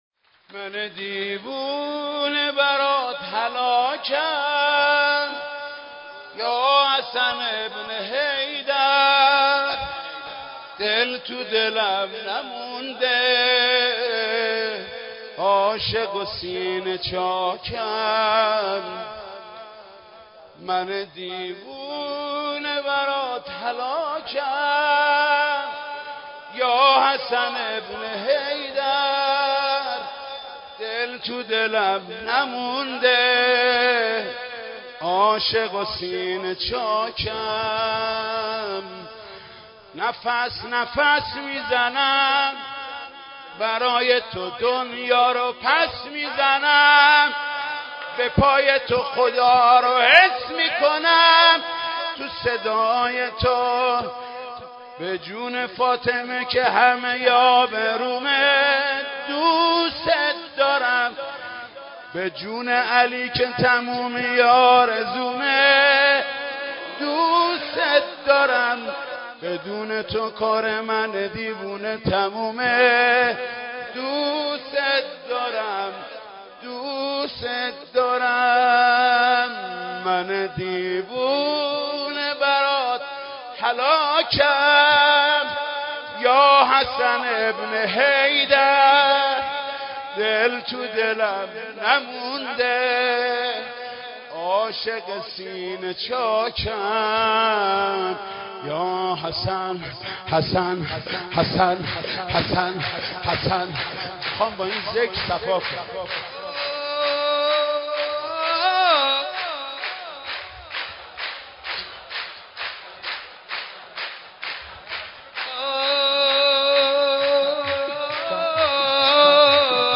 شب پانزدهم رمضان با مداحی حاج سعید حدادیان -حاج محمود کریمی در مهدیه امام حسن مجتبی(ع) برگزار گردید.
مولودی
مدح خوانی